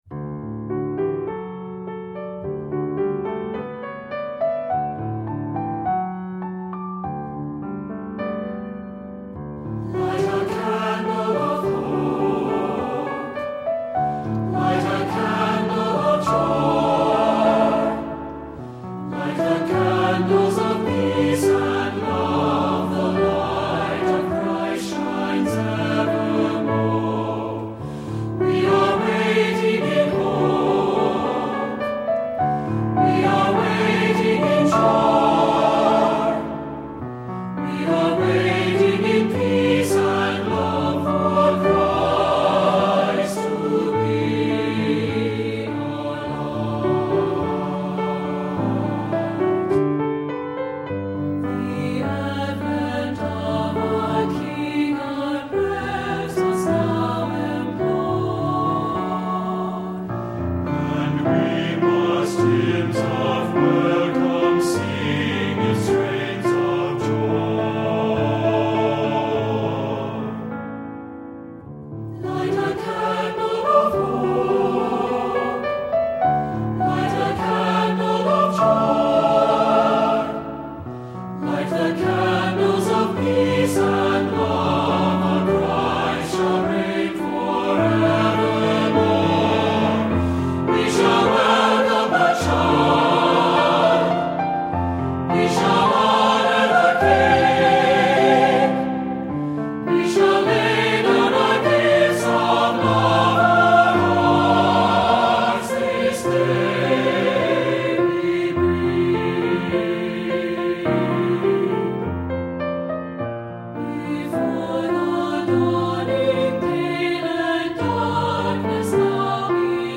SAB Church Choir Music
Voicing: SATB